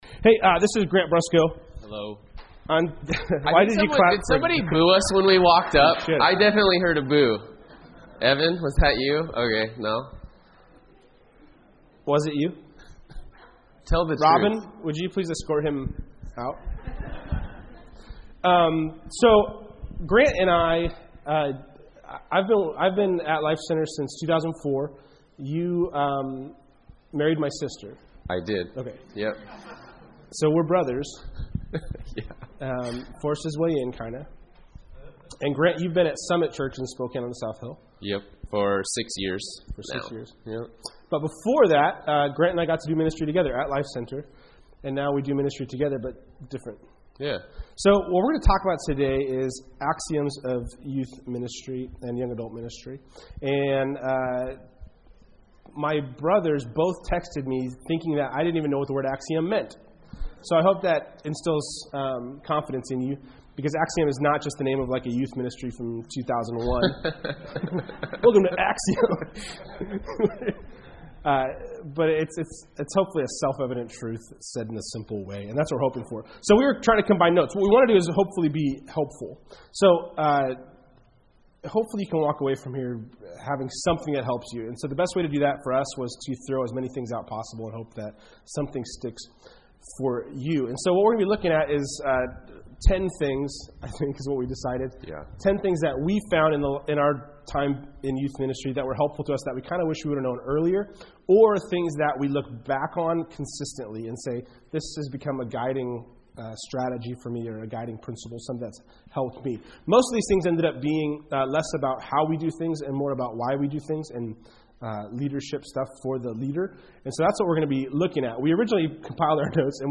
Workshop: Top 10 axioms of youth and young adult ministry